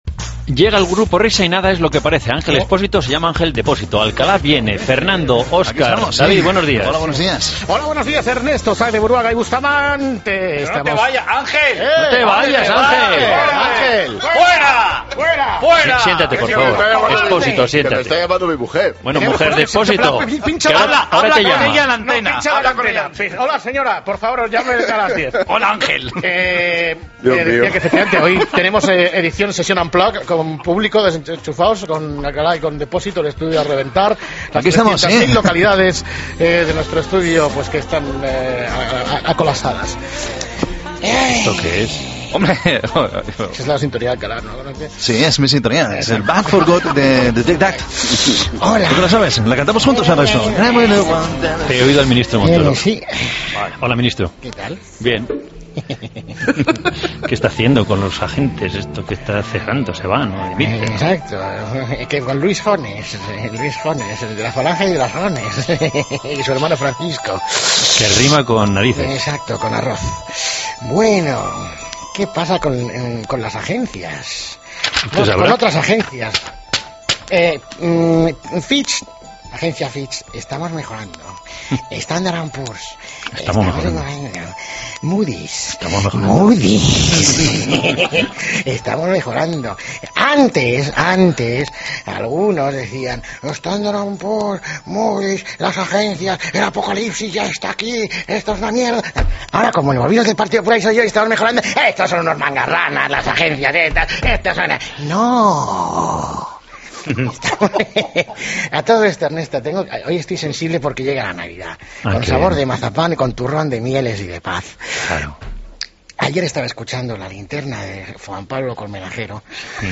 AUDIO: Qué les pasa en la voz a los compañeros de radio cuando tienen que hablar de Montoro...?